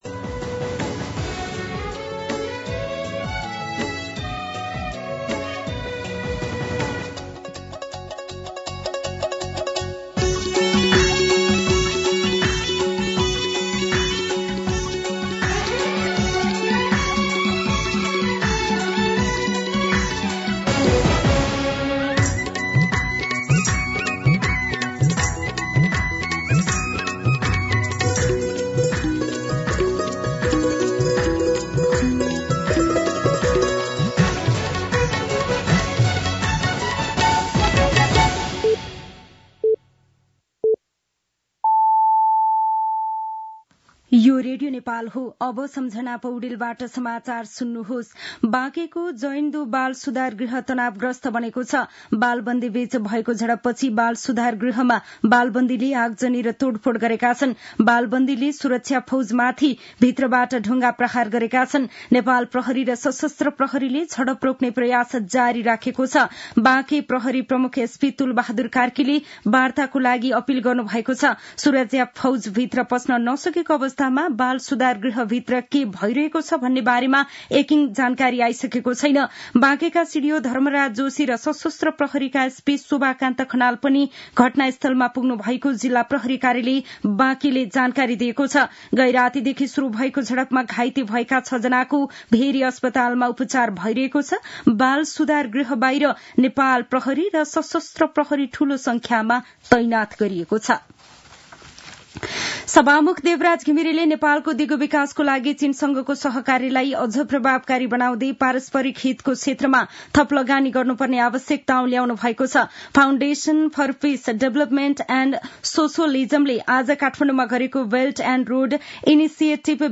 दिउँसो ४ बजेको नेपाली समाचार : २ चैत , २०८१
4-pm-Nepali-News-1.mp3